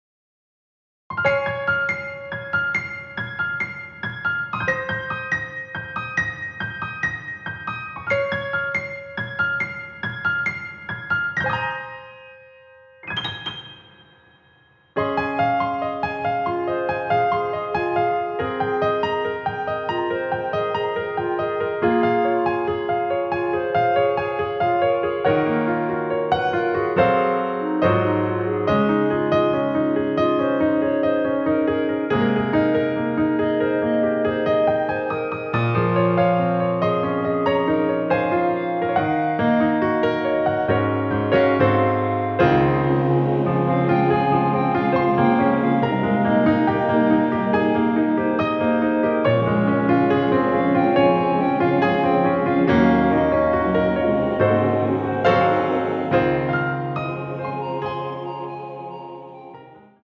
원키 멜로디 포함된 MR입니다.
Ab
앞부분30초, 뒷부분30초씩 편집해서 올려 드리고 있습니다.
중간에 음이 끈어지고 다시 나오는 이유는